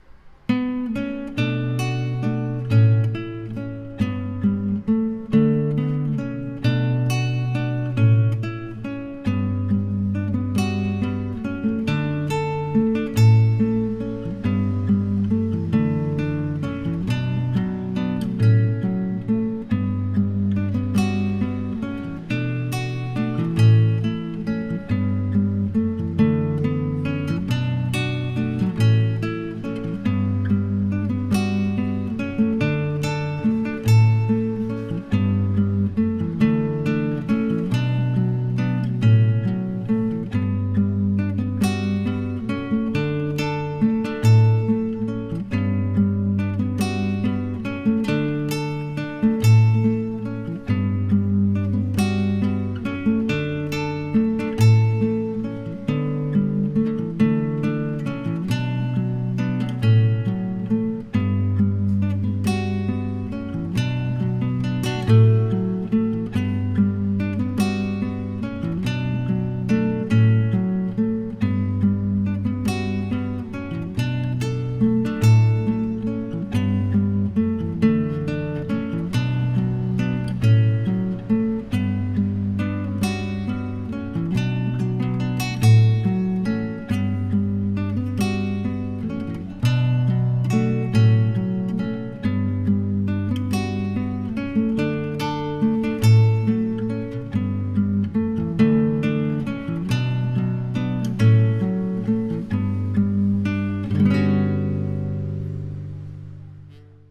El acompañamiento: